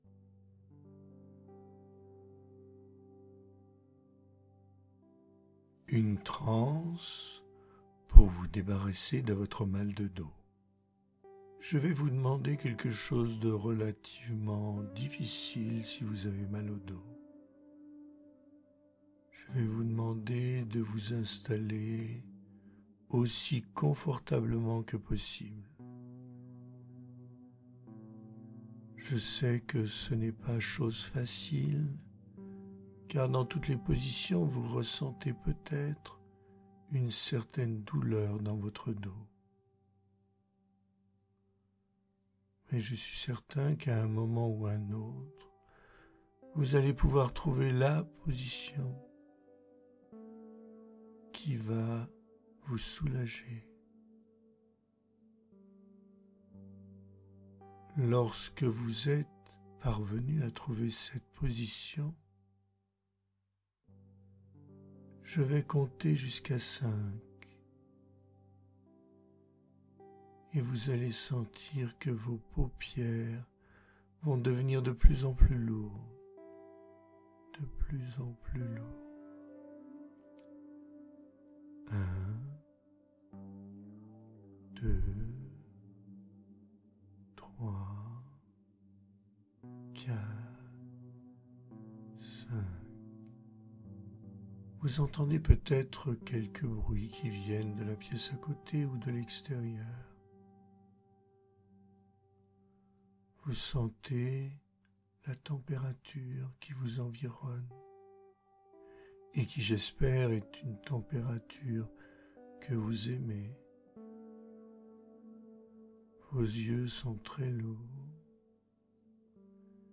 Voici les pistes des séances d’autohypnose.